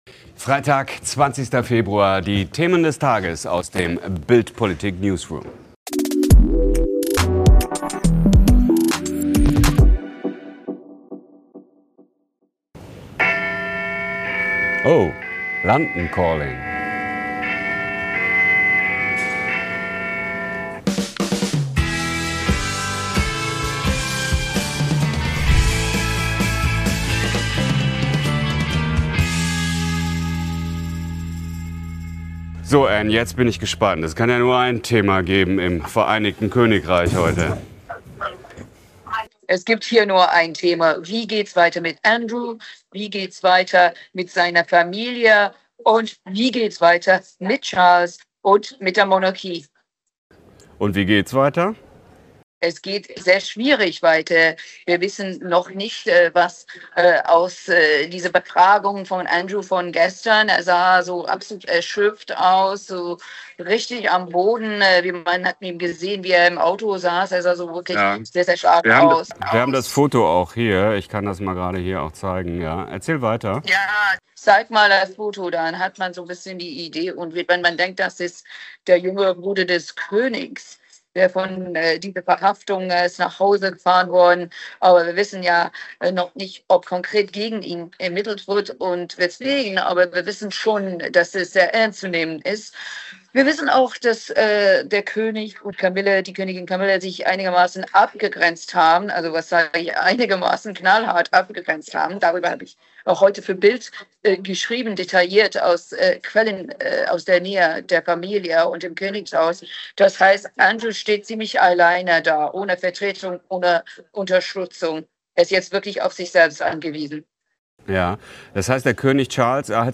Im Vertraulich-Vodcast Global kommentiert Reporterin Anne McElvoy das zerrüttete Verhältnis des Königs zum Ex-Prinzen.